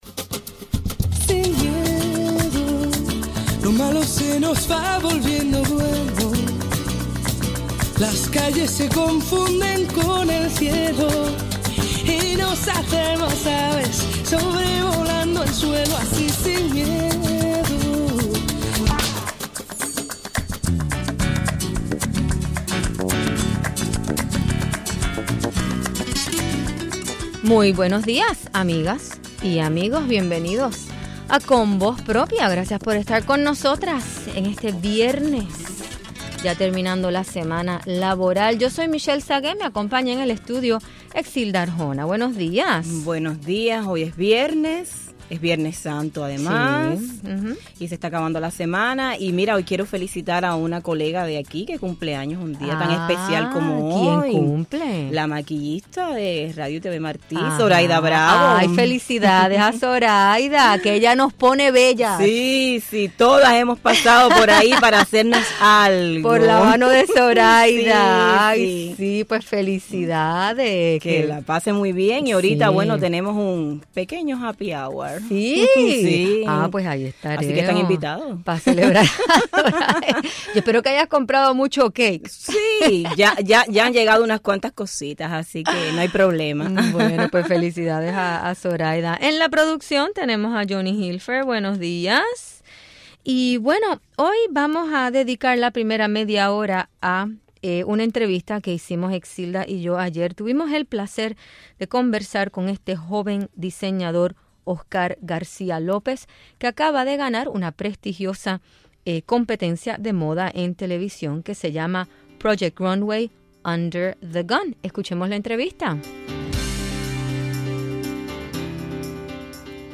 Hoy entrevistamos al diseñador cubano